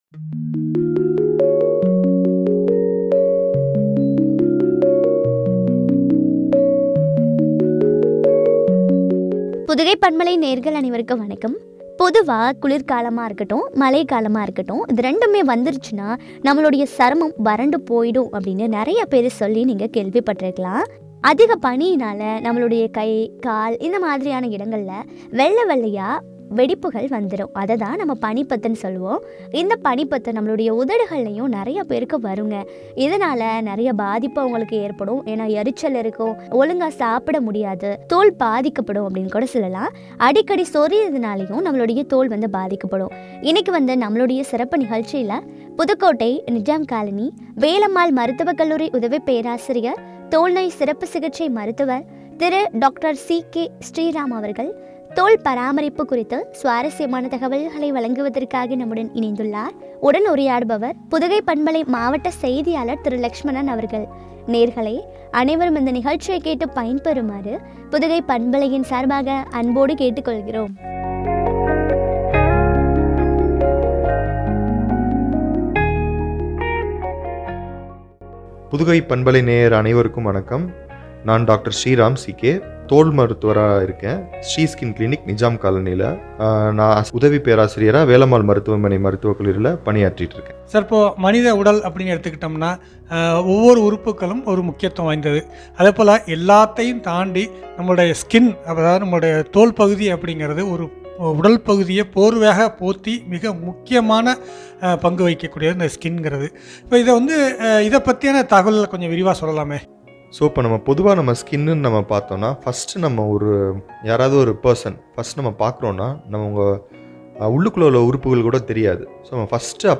பாதுகாப்பும் ” குறித்து வழங்கிய உரையாடல்.